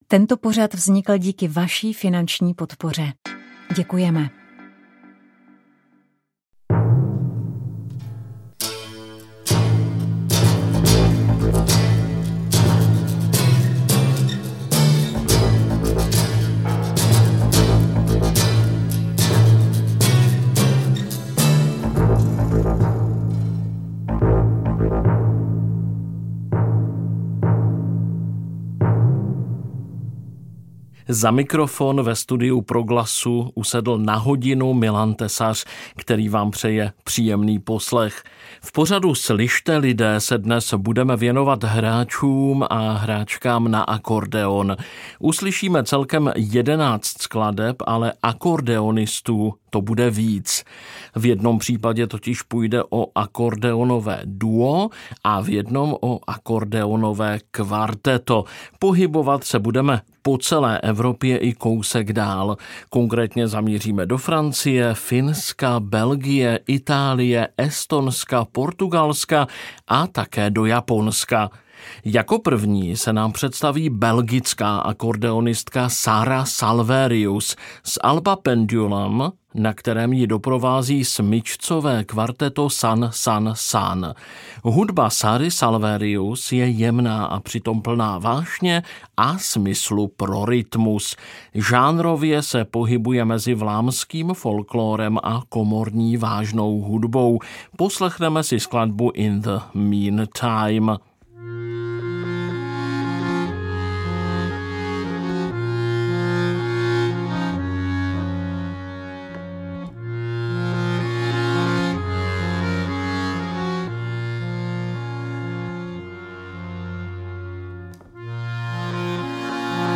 původem srbská hráčka na pětistrunnou violu a zpěvačka